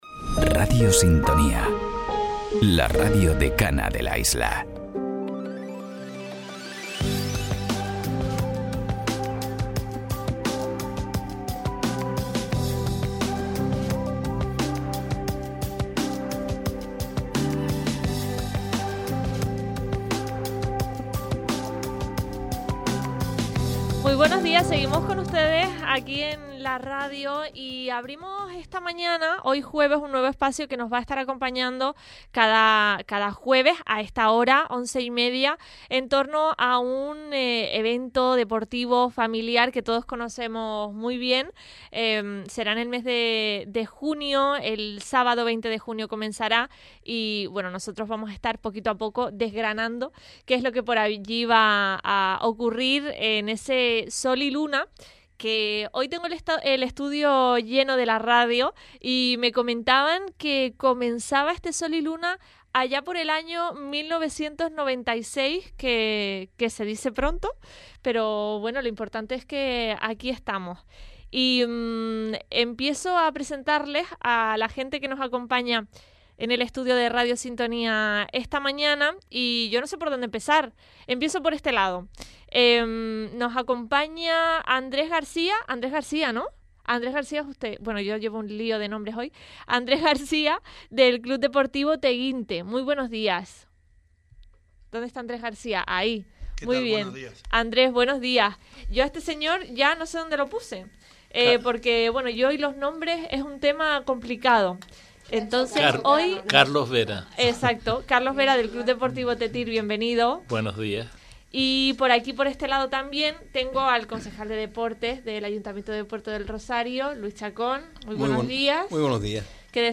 Entrevista a participantes y organizadores del evento Sol y Luna - 23.04.26 - Radio Sintonía